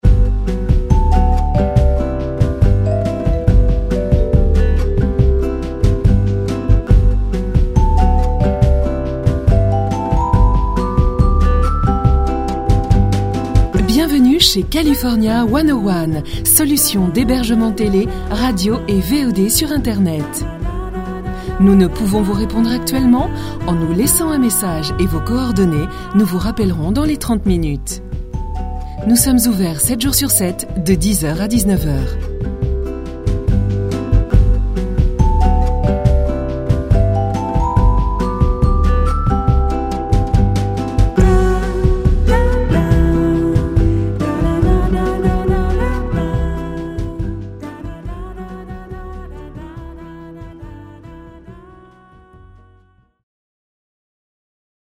Sprechprobe: eLearning (Muttersprache):
My voice is medium, clear, smiling, with well-educated, well pronounced accent.